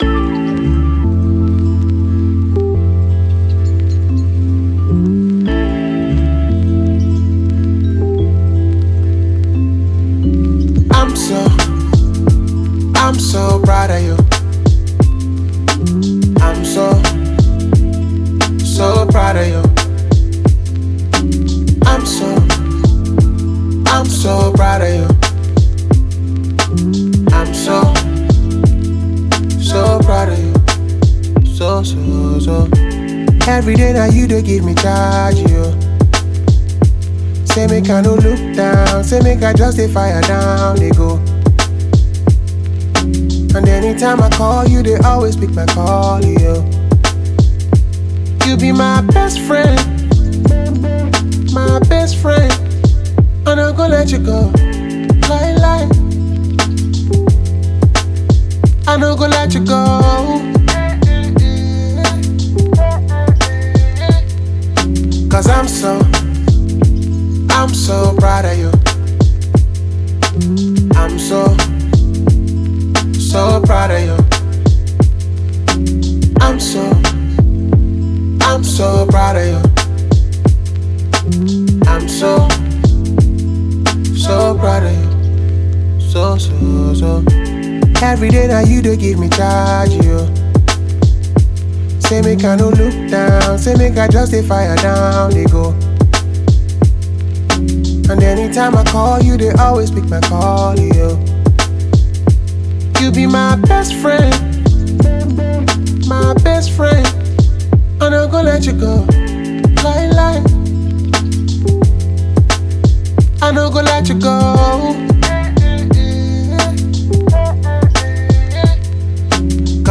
R&B
A minor